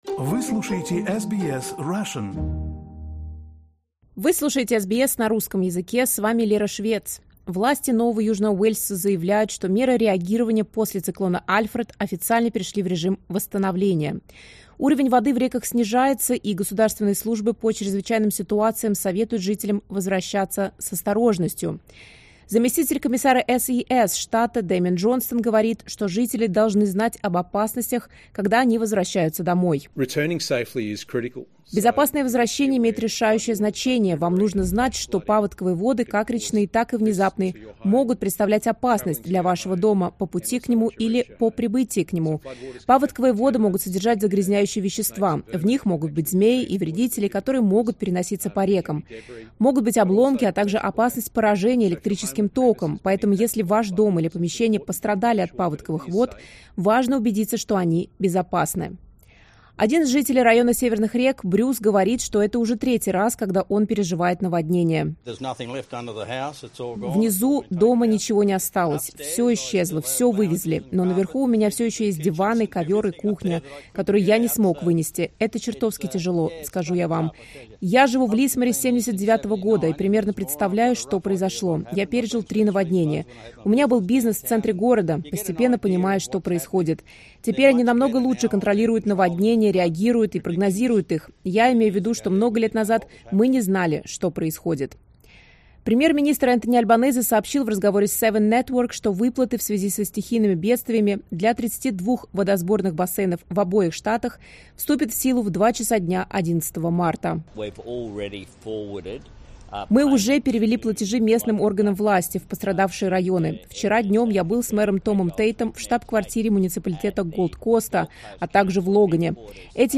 После циклона «Альфред» продолжаются сильные дожди на большой части юго-востока Квинсленда и севера Нового Южного Уэльса. Жители штата Квинсленд рассказали SBS Russian об обстановке в своих городах и о том, как они пережили циклон.